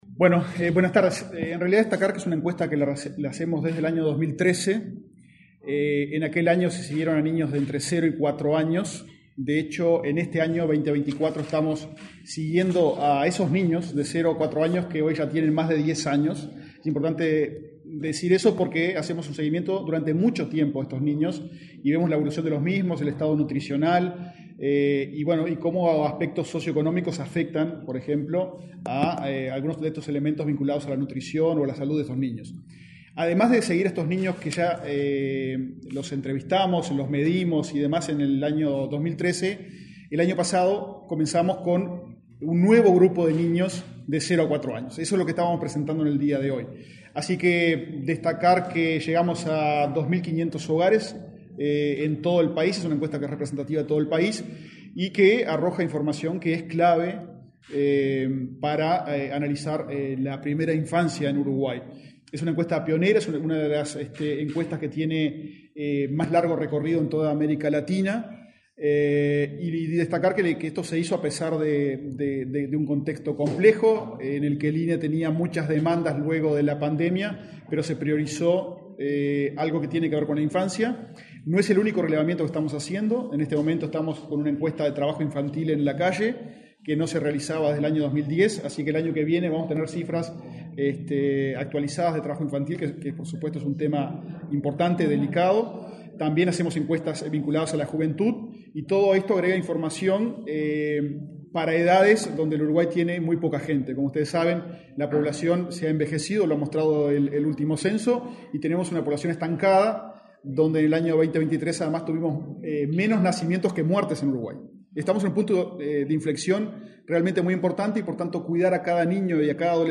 Declaraciones del director del INE, Diego Aboal
Declaraciones del director del INE, Diego Aboal 05/11/2024 Compartir Facebook X Copiar enlace WhatsApp LinkedIn El Ministerio de Salud Pública (MSP), el de Desarrollo Social (Mides) y el Instituto Nacional de Estadística (INE) presentaron, este martes 5 en Montevideo, los datos de la Encuesta de Nutrición, Desarrollo Infantil y Salud, cohorte 2023. Luego, el titular del INE, Diego Aboal, dialogó con la prensa.